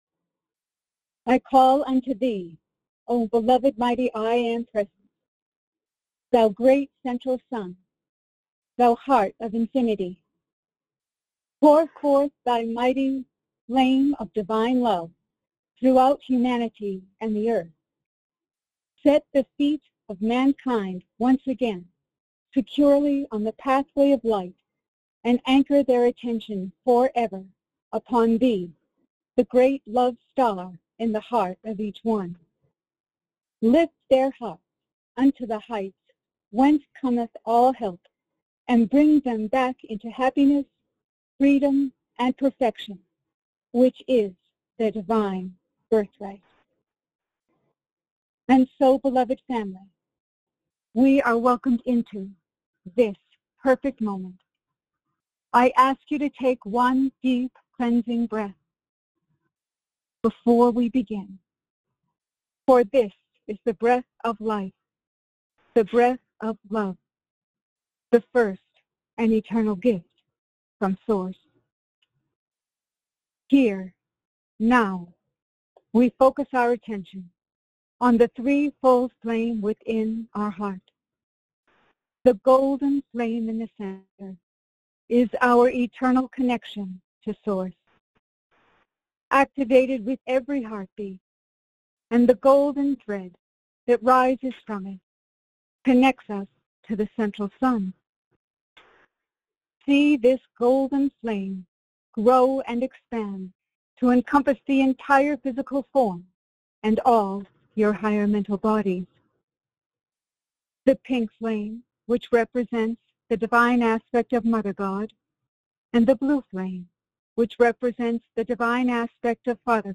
Join in and follow along in group meditation with Master Saint Germain.